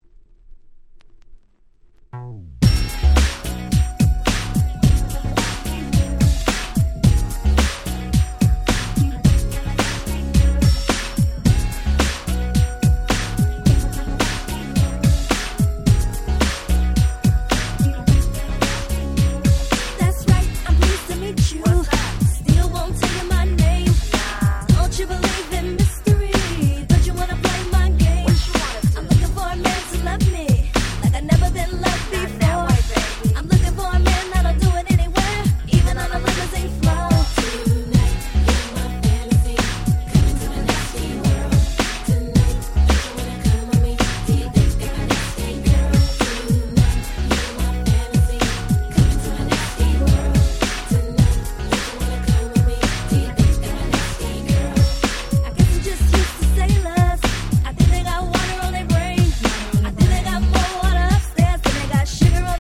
95' Smash Hit R&B !!